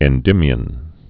(ĕn-dĭmē-ən)